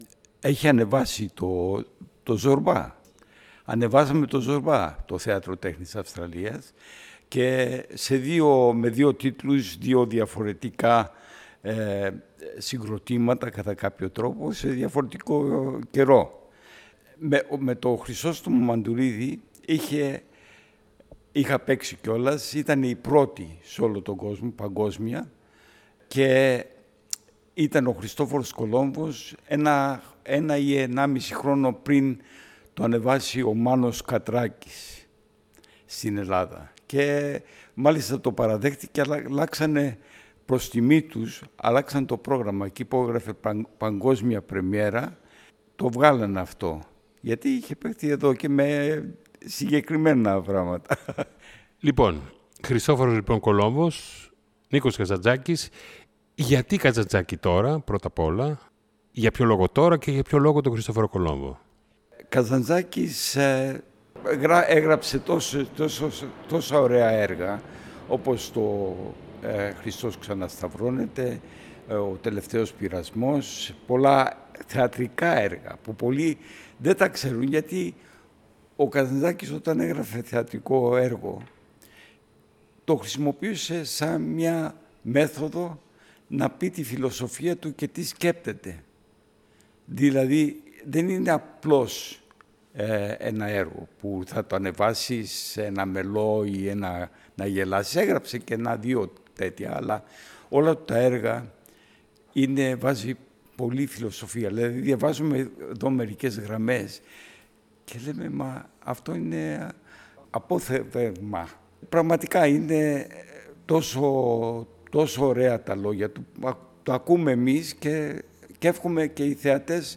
Η συνέντευξη μεταδόθηκε στην ραδιοφωνική εκπομπή ” Νυκτερινοί Περίπατοι Ραδιοφώνου”, της Πέμπτης 03/04/25 στο ελληνόφωνο ραδιοσταθμό του Σίδνεί https